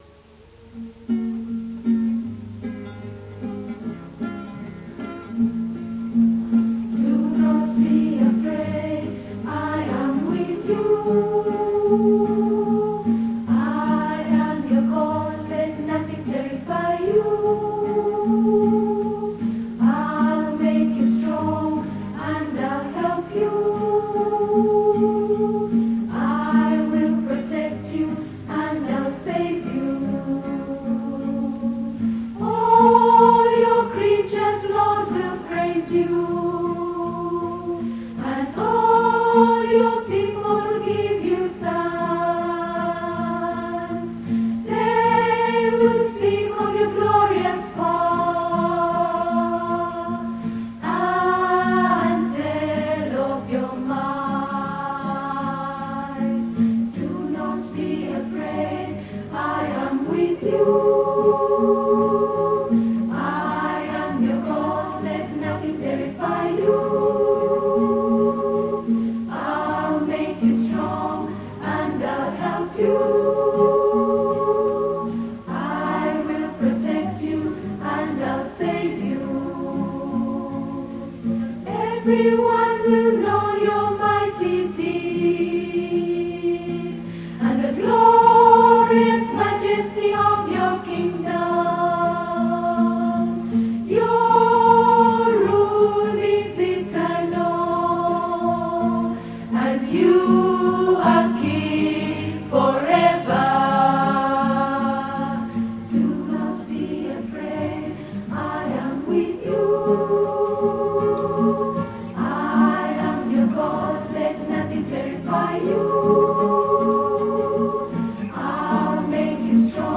In their Hour of Praise they sang 27 items.